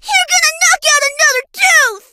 bonni_hurt_vo_02.ogg